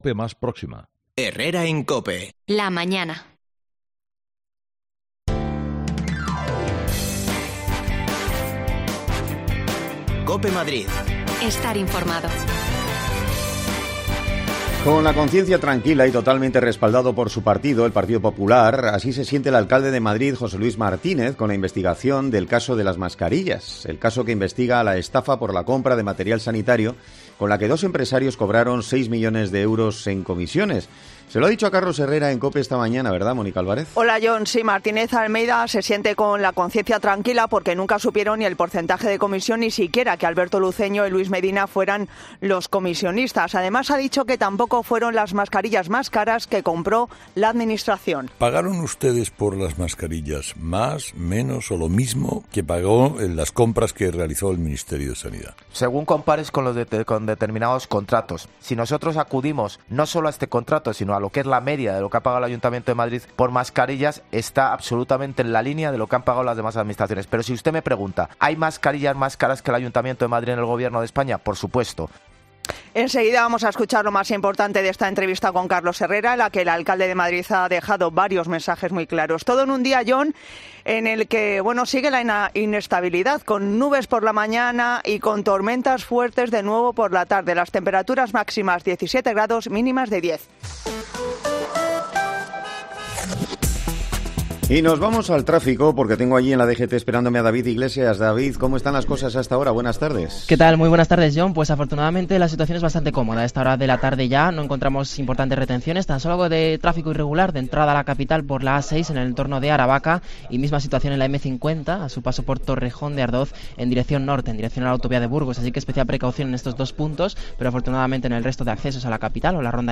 AUDIO: El alcalde de Madrid Jose Luis Martinez ha pasado por los micrófonos de Cope para dar explicaciones sobre el caso de las mascarillas...